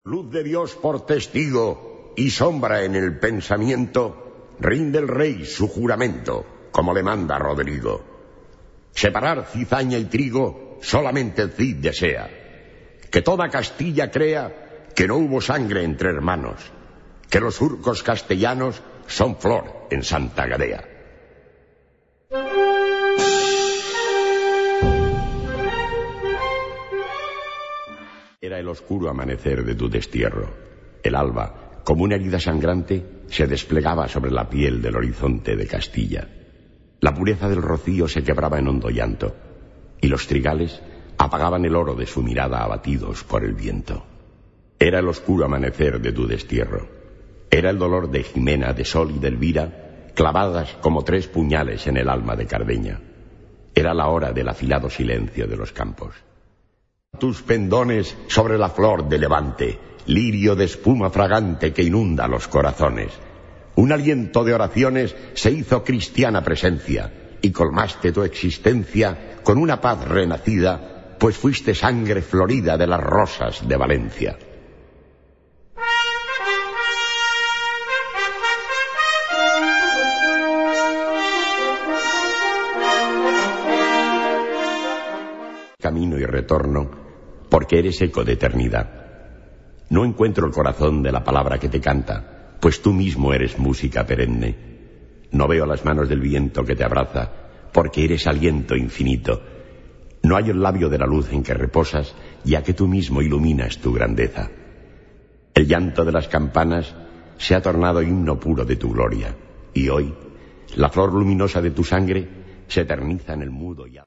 Categorie Harmonie/Fanfare/Brass-orkest
Bezetting Ha (harmonieorkest); SprS (verteller)